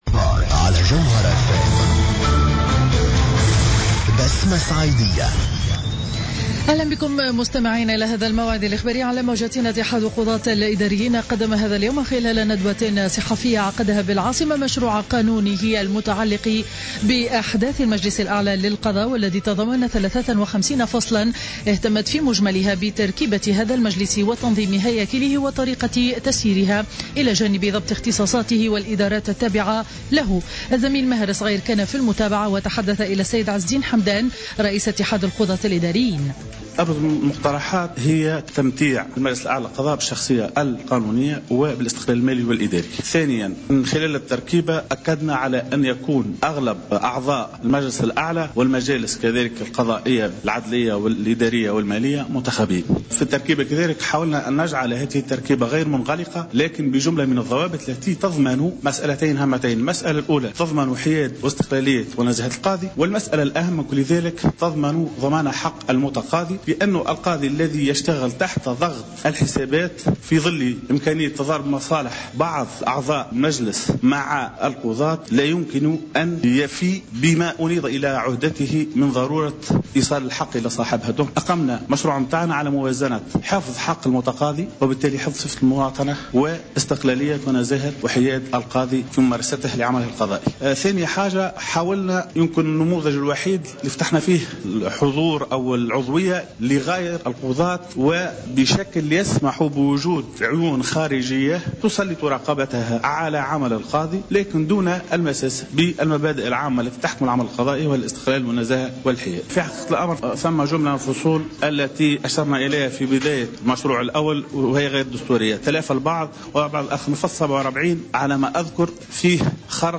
نشرة أخبار منتصف النهار ليوم الجمعة 13 مارس 2015